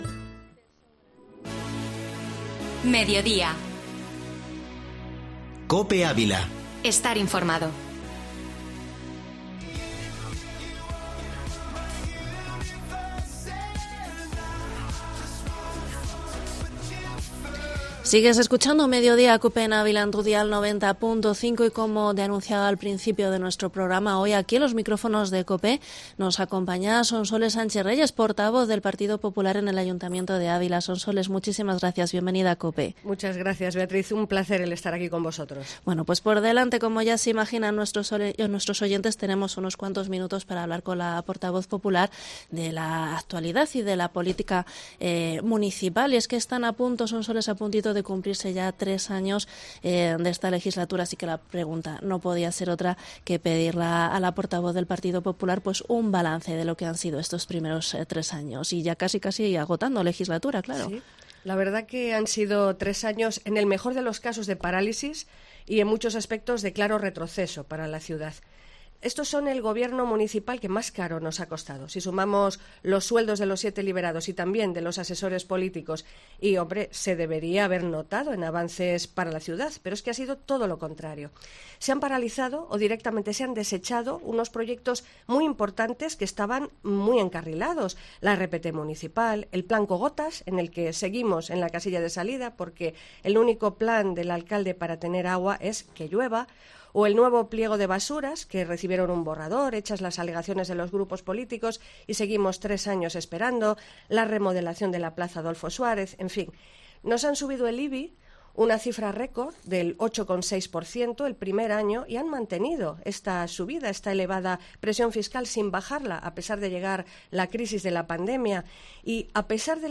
Entrevista Sonsoles Sánchez-Reyes, portavoz del PP en el Ayuntamiento de Ávila